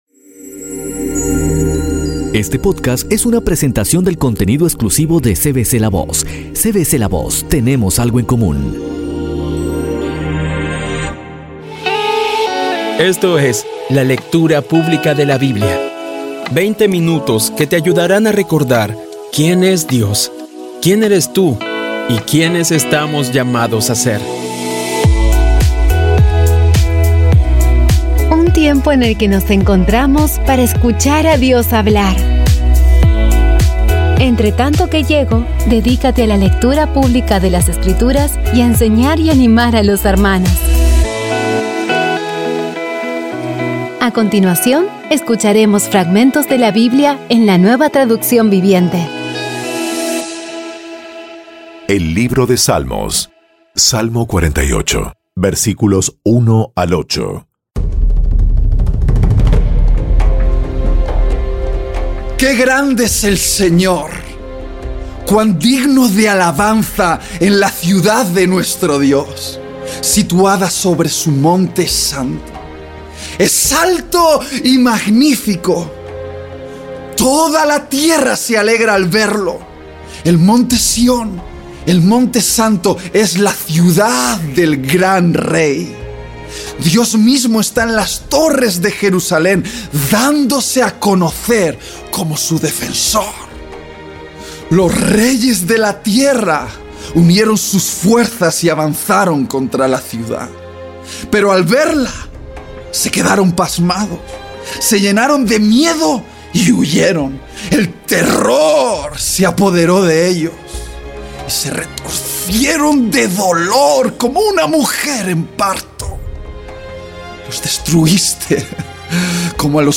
Audio Biblia Dramatizada Episodio 107
Poco a poco y con las maravillosas voces actuadas de los protagonistas vas degustando las palabras de esa guía que Dios nos dio.